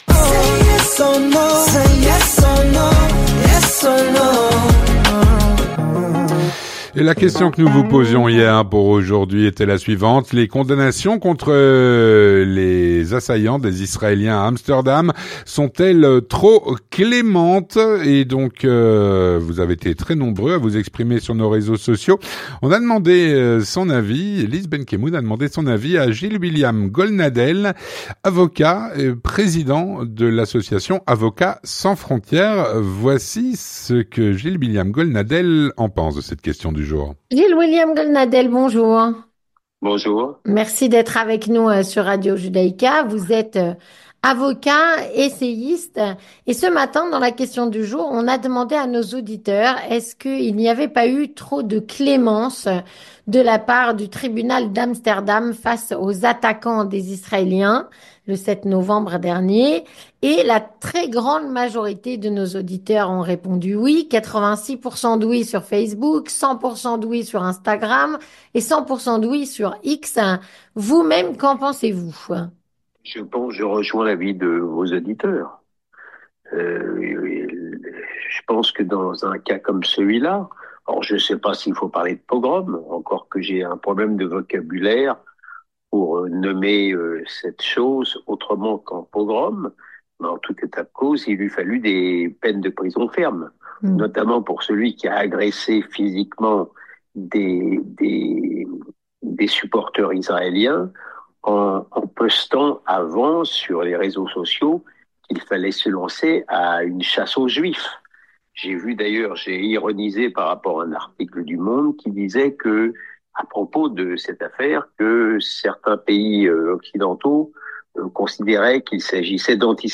Gilles-William Goldnadel, avocat, Président de l'Association "Avocats sans frontières”, répond à "La QSuestion Du Jour".